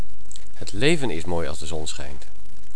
accent op leven